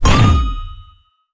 magic_deflect_spell_impact1.wav